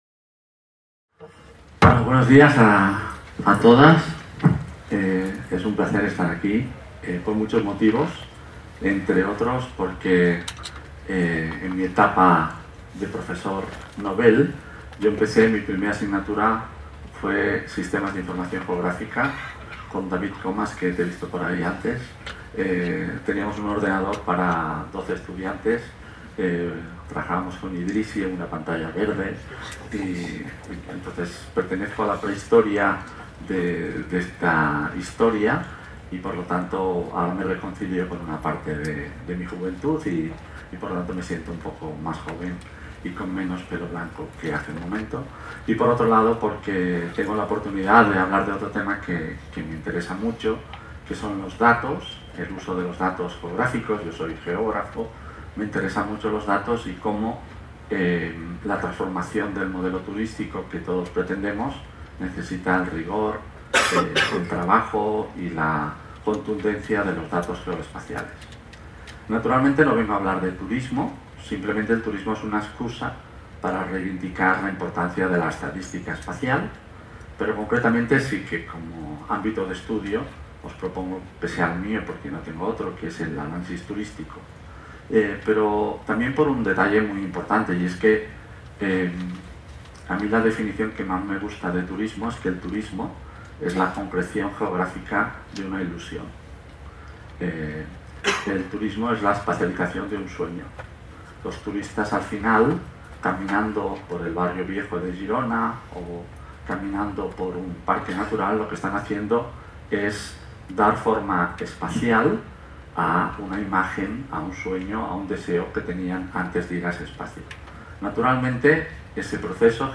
Sessió plenària del doctor José Antonio Donaire en el marc de les 18enes Jornades de SIG Lliure 2025 organitzades pel SIGTE de la Universitat de Girona. El doctor donaire és el comisionat per a la gestió de turisme sostenible de Barcelona i directori de l'Institut d'Investigació en Turisme (INSETUR) parla sobre la importància d'analitzar les dades geogràfiques, obtenir-ne estadístiques i així poder gestionar millor el turisme  This document is licensed under a Creative Commons: Attribution – Non commercial – Share alike (by-nc-sa) Show full item record